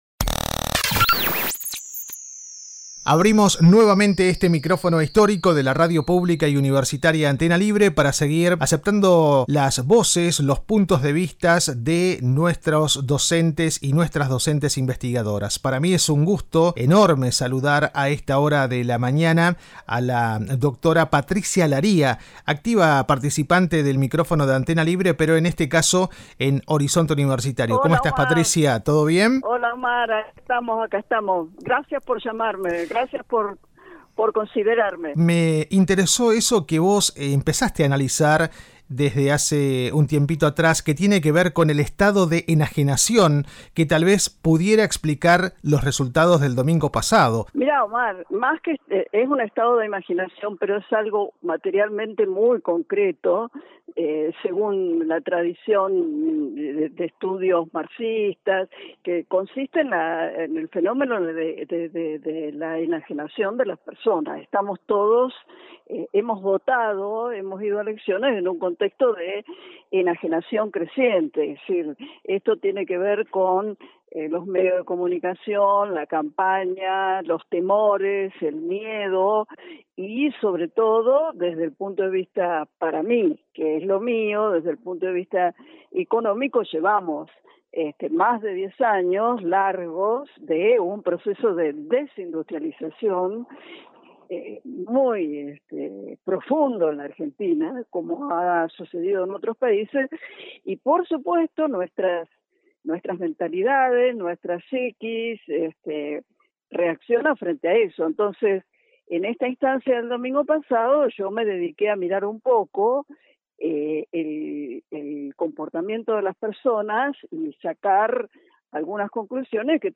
En Horizonte Universitario, convocamos a las voces expertas de nuestra Casa de Estudios para descifrar las claves de unos comicios que marcarán el rumbo del país.